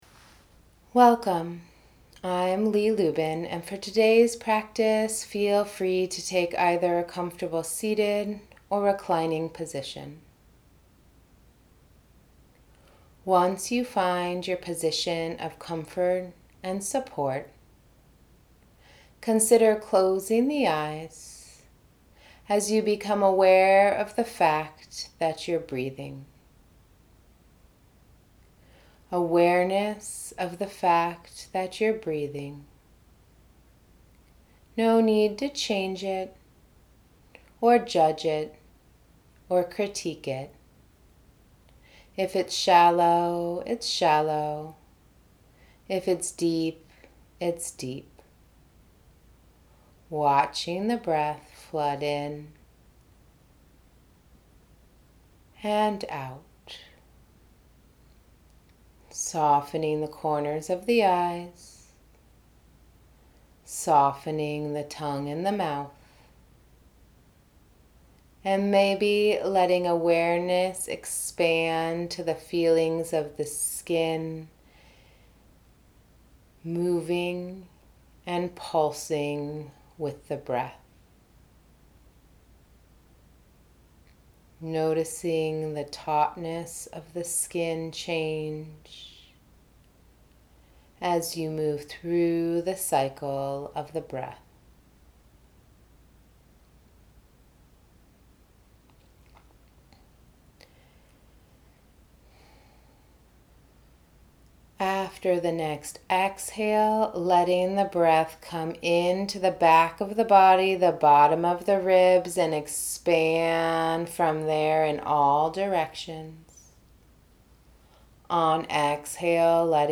Guided Ujjayi to Cultivate Support and Warmth